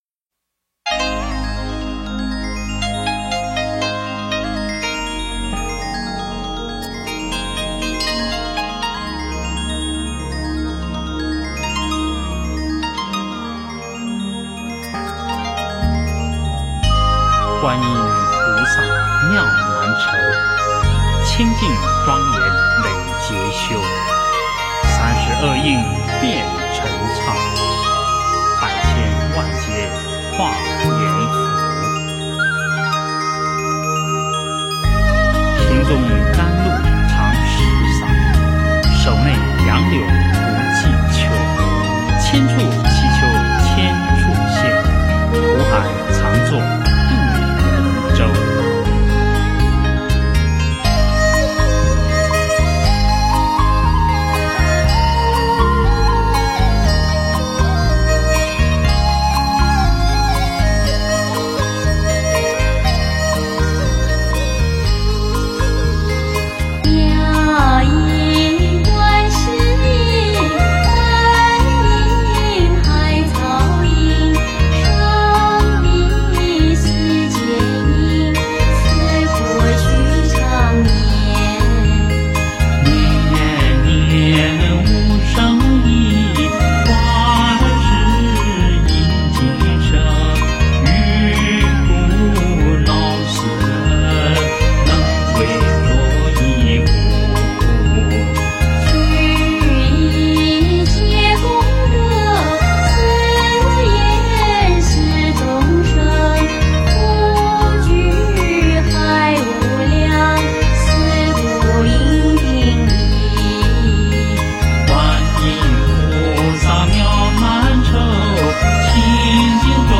观音行 诵经 观音行--佛教音乐 点我： 标签: 佛音 诵经 佛教音乐 返回列表 上一篇： 观音普门品 下一篇： 南無大悲观世音 相关文章 The Other Side--The Buddhist Monks The Other Side--The Buddhist Monks...